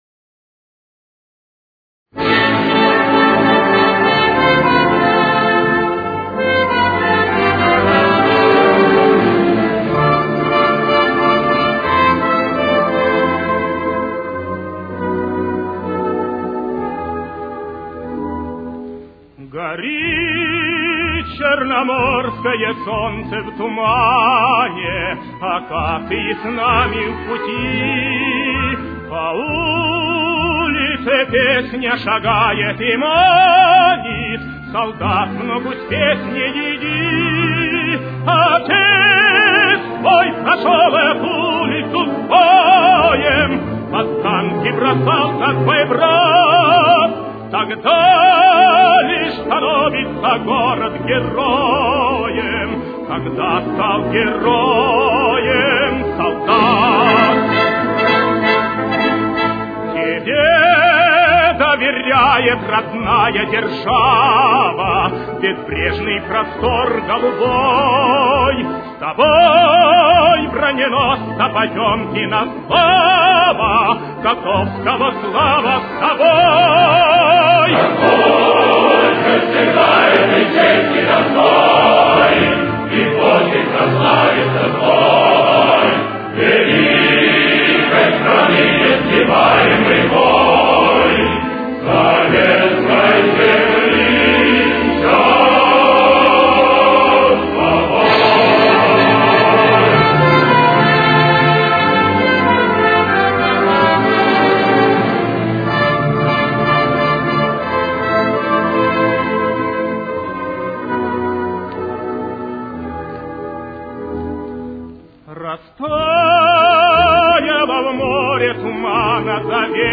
Темп: 195.